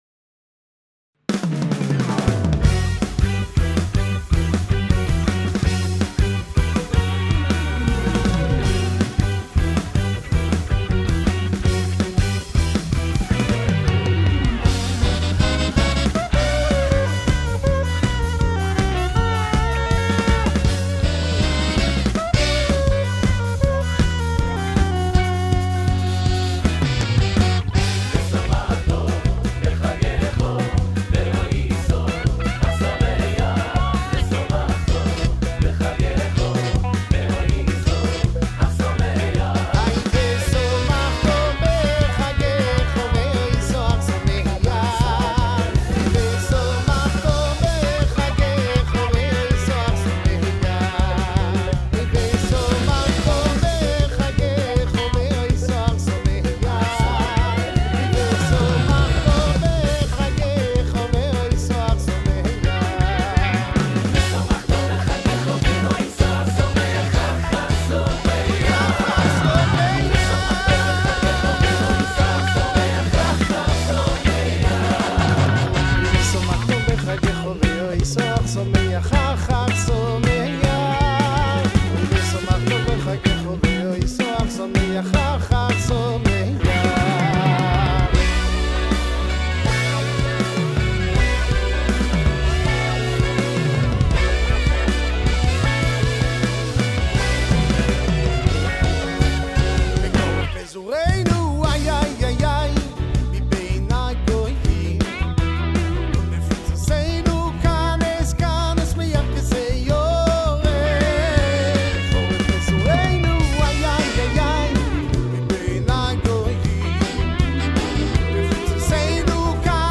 מקהלה חסידית ויוצר אוירה יהודית